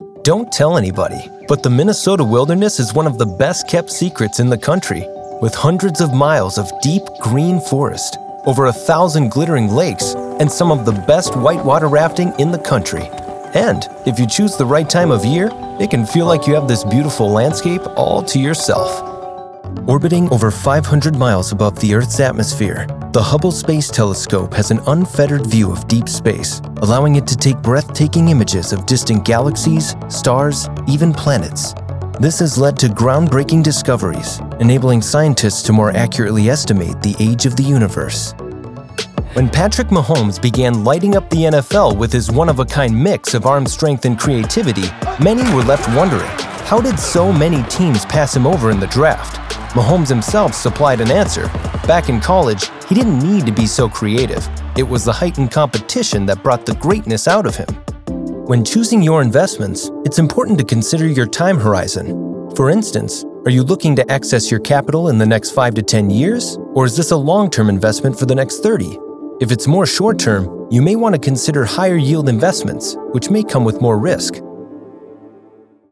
Narration Demo
English - USA and Canada
Young Adult
Middle Aged